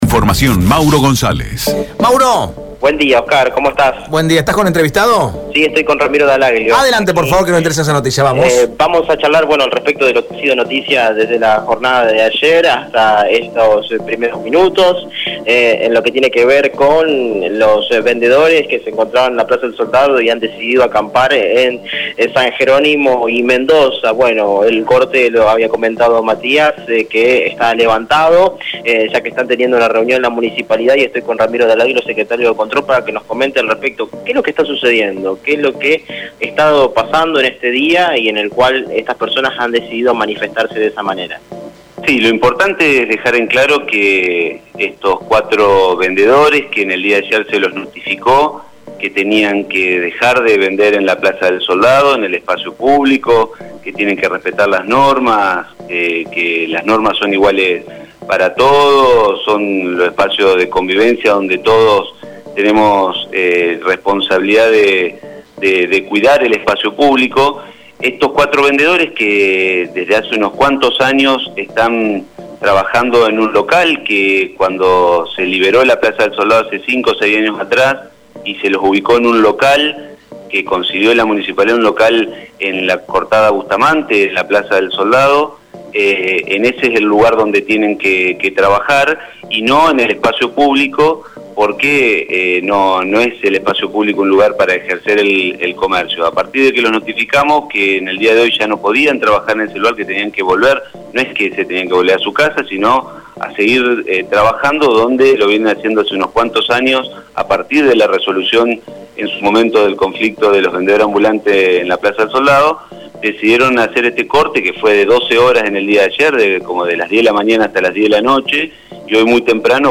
El secretario de Control de la Municipalidad de Santa Fe, Ramiro Dall’Aglio, habló en RADIO EME sobre la protesta de puesteros de Plaza del Soldado.
AUDIO RAMIRO DALL’ AGLIO POR RADIO EME: